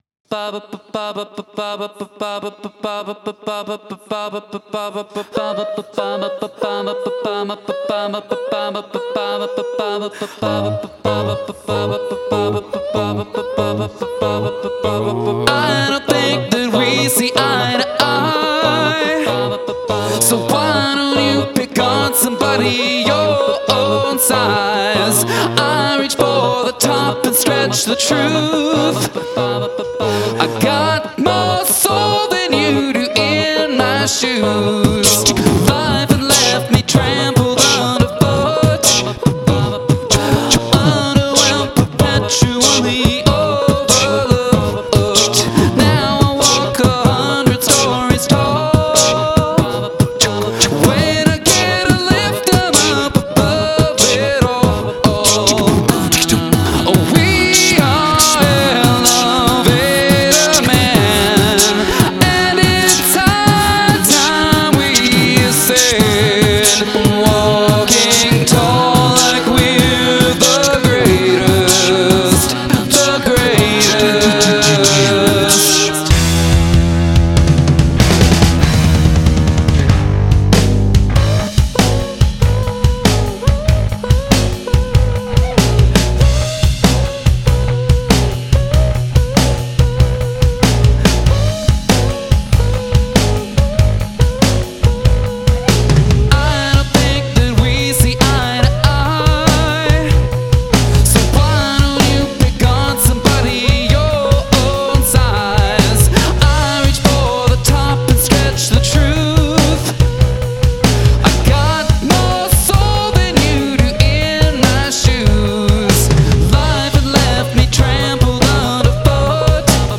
Prominent musical repetition